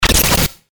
FX-844-BREAKER